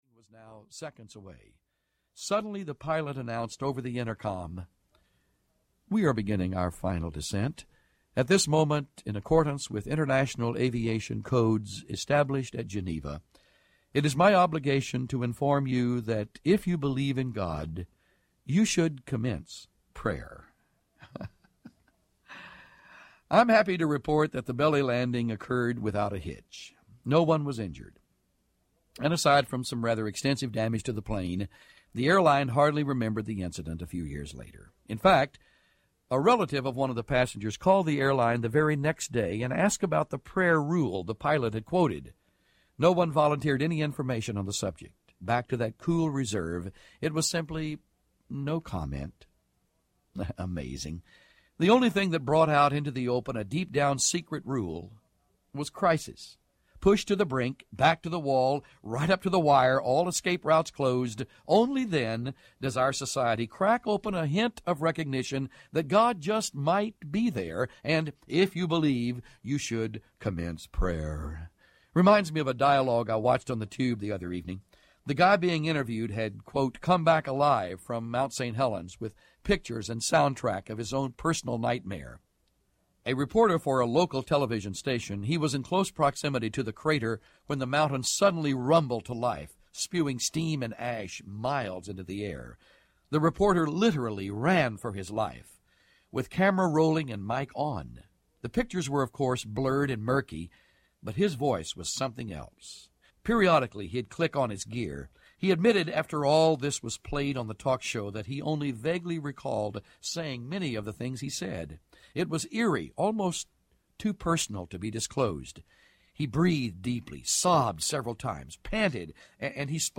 Growing Strong in the Seasons of Life Audiobook